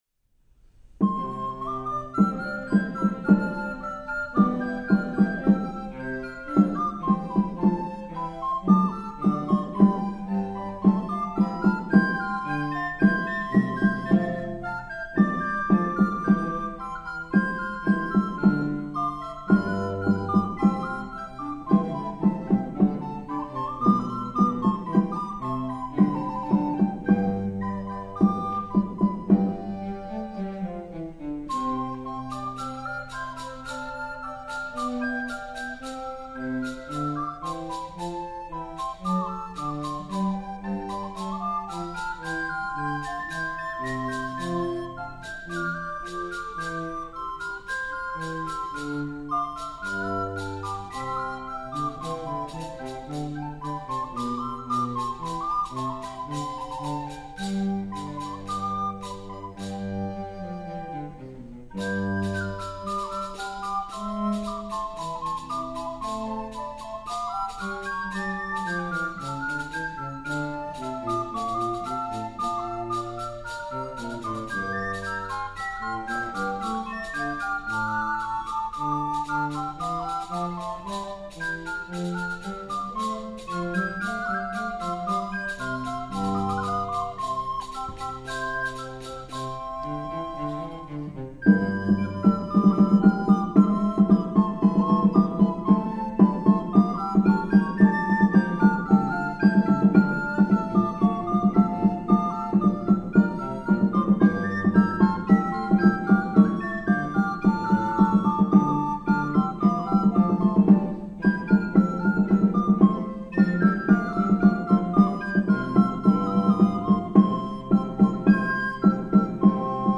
Audio examples from a recent recital:
"Air" from The Faerie Queen by Henry Purcell is a sprightly movement for recorders, cello, and percussion.